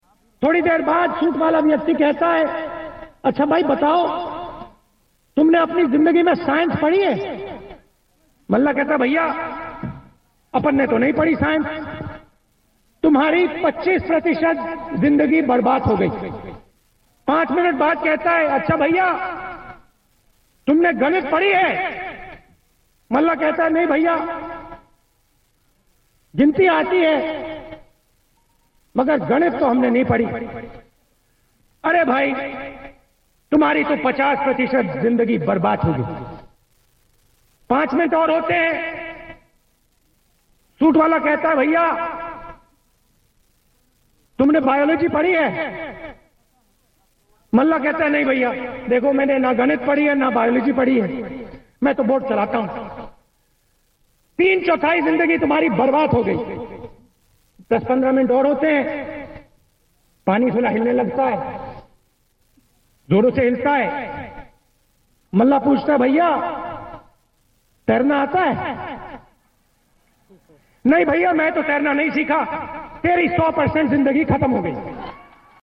चंपारण की रैली में राहुल ने मोदी को सूट-बूट वाला कहा और जनता को सुनाया एक चुटकुला.